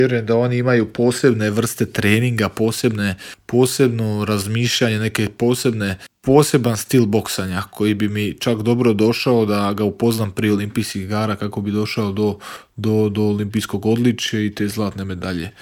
O svom putu do svjetske bronce govorio je naš najbolji boksač u Intervjuu Media servisa.